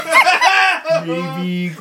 hahaha_hEErDS0.mp3